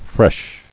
[fresh]